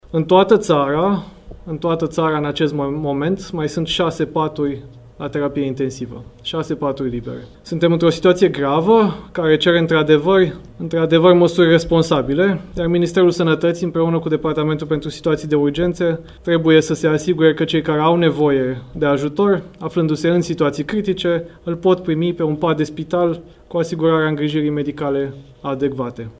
În toată ţara, în acest moment, mai sunt şase paturi la Terapie intensivă, şase paturi libere”, a spus Vlad Voiculescu într-o declaraţie de presă.